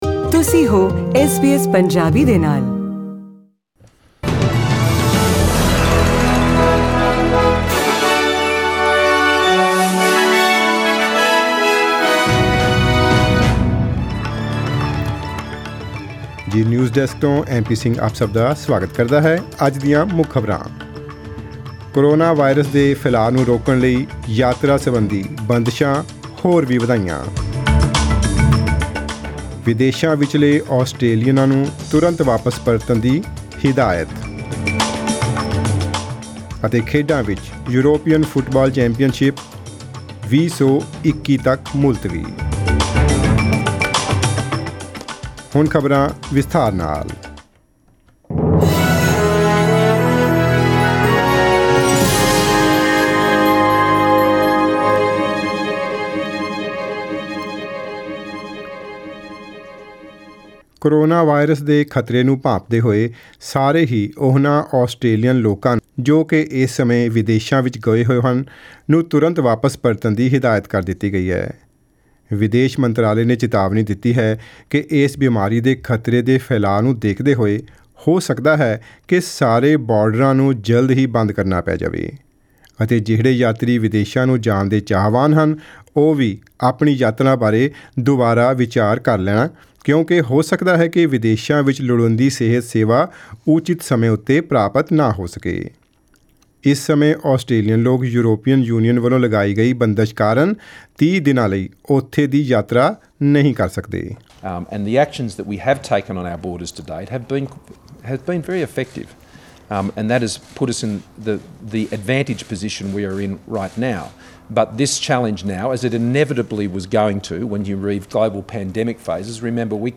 Australian News in Punjabi: 18 March 2020